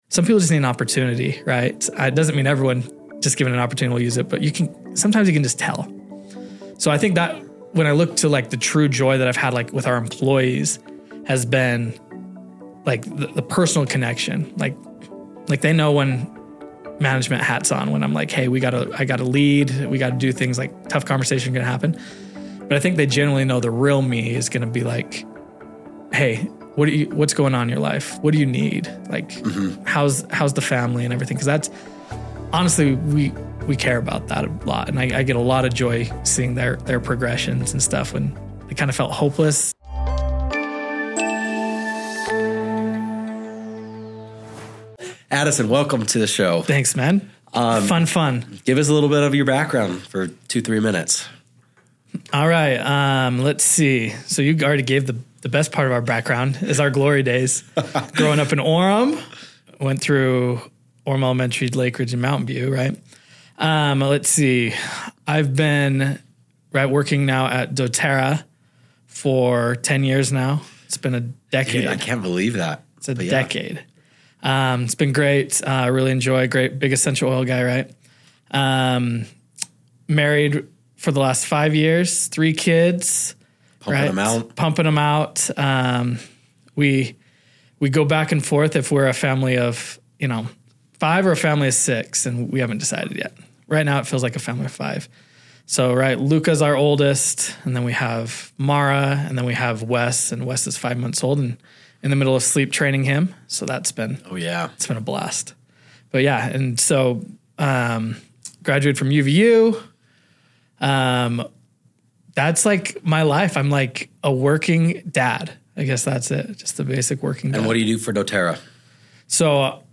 Welcome to "Deeper Than Dough," where meaningful conversations meet the intersection of joy, purpose, and success.